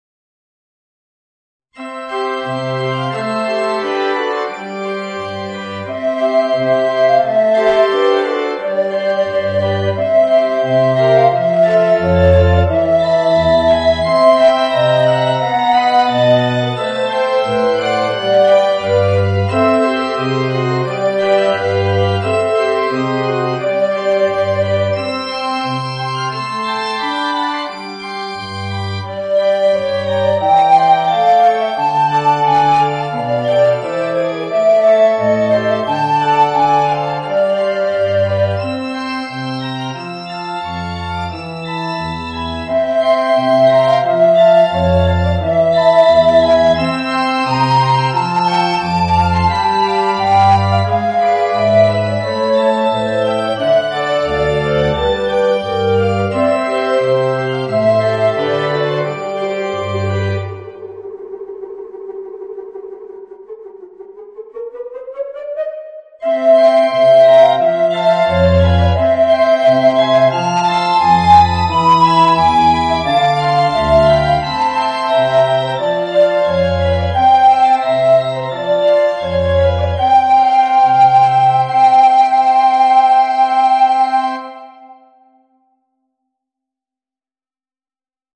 Voicing: Alto Recorder and Organ